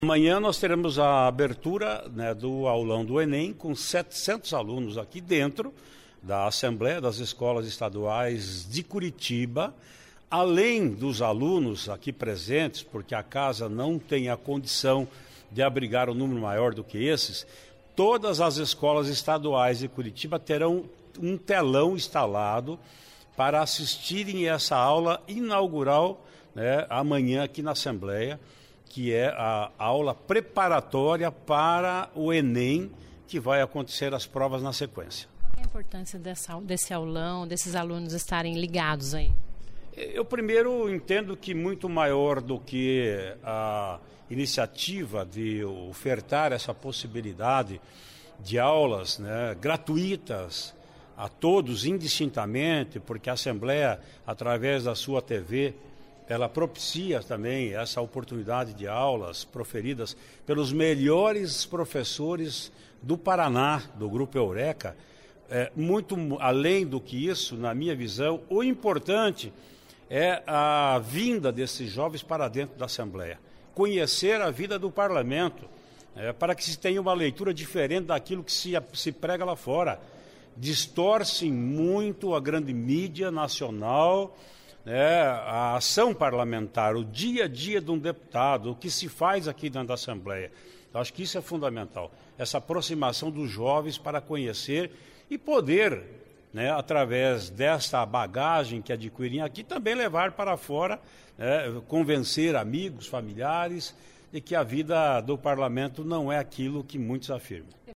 Ouça o que diz o presidente da Assembleia Legislativa sobre as novidades desta terceira edição do aulão do ENEM, que deve reunir milhares de estudantes dentro e fora da Assembleia para assistir às aulas ministradas pelos professores do grupo Eureka.
(Sonora)